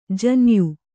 ♪ janyu